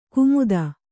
♪ kumuda